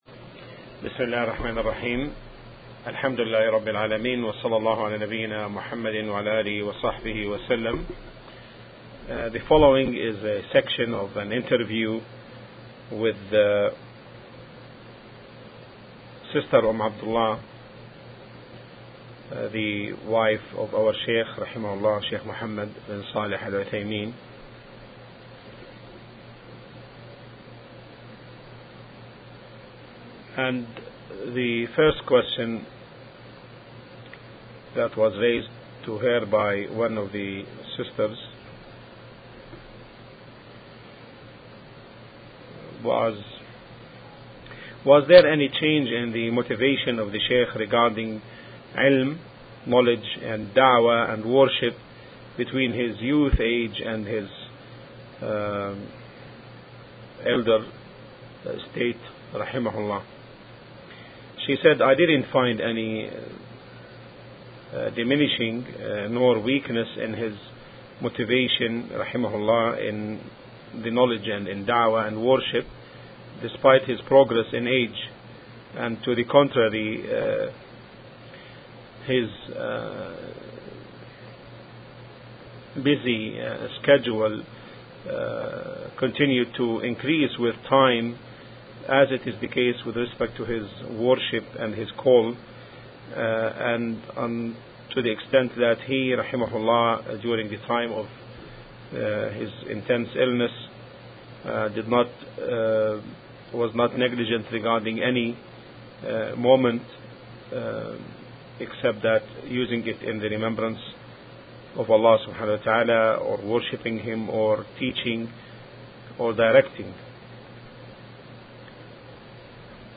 Interview with Sh.